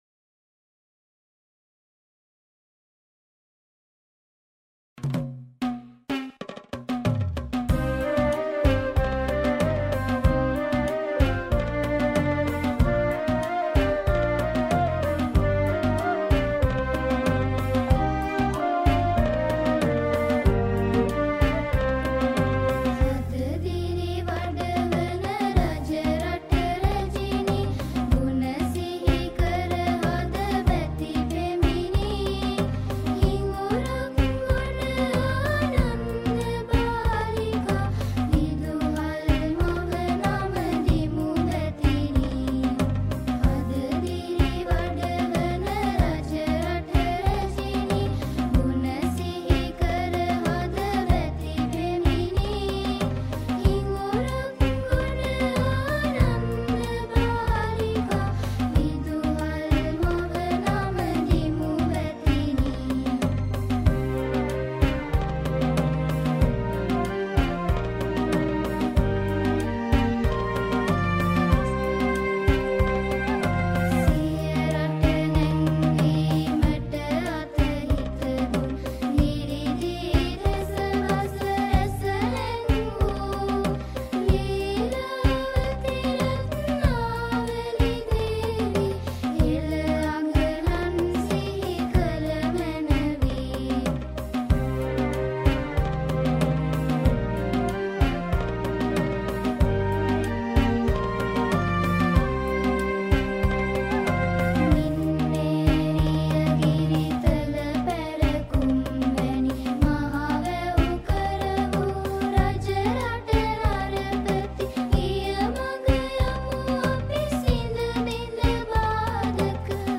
School Anthem